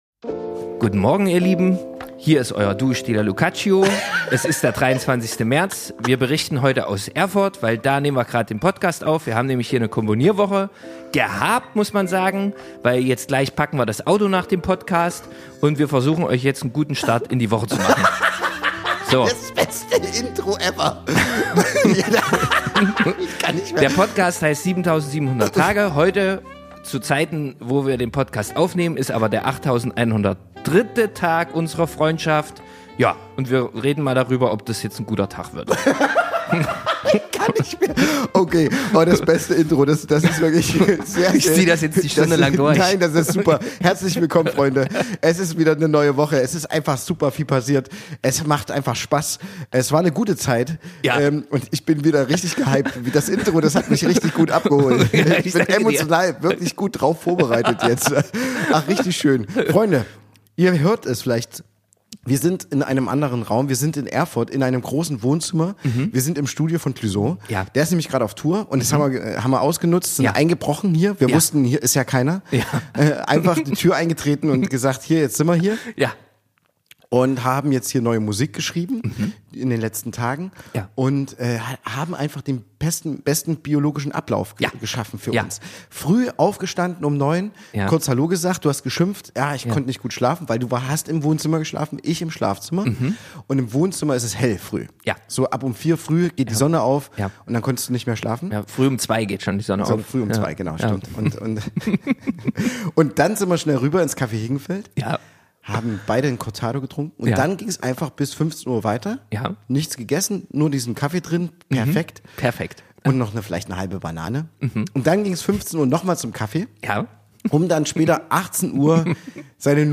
Live aus unserem Studio nehmen wir euch mit ins Innere des Duos.